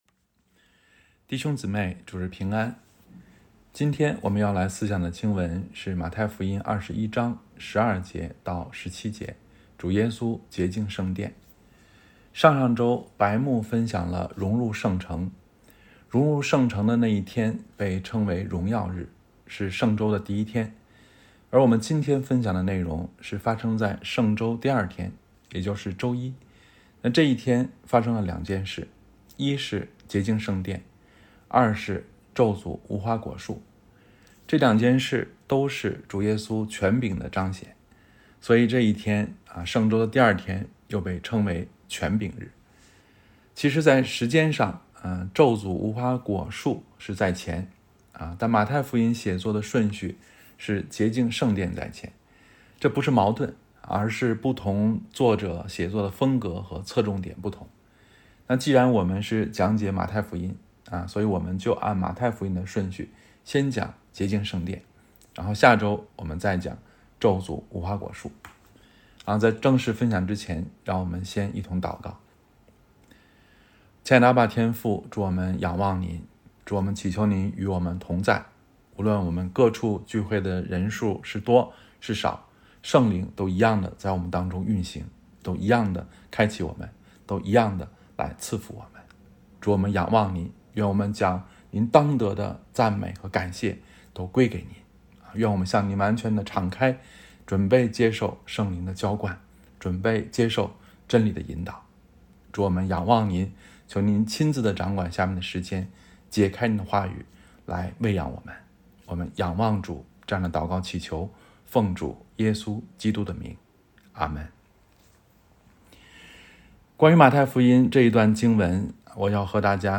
洁净圣殿——2026年4月12日主日讲章